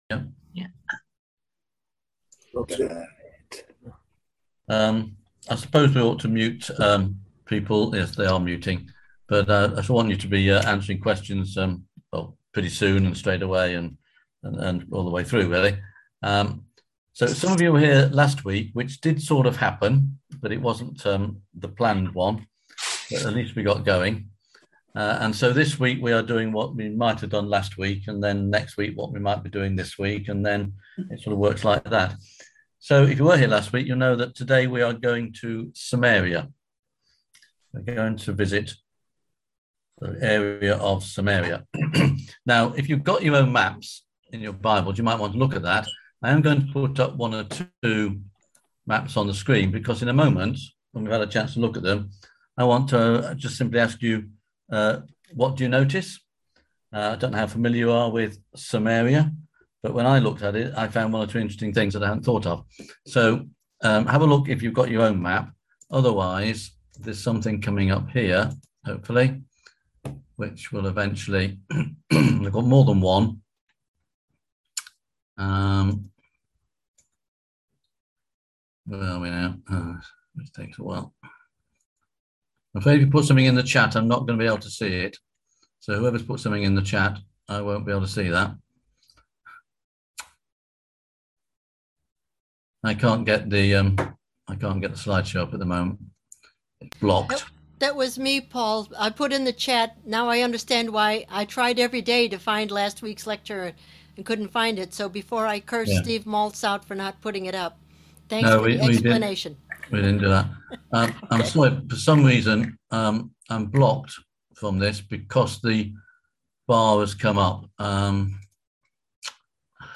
On June 9th at 7pm – 8:30pm on ZOOM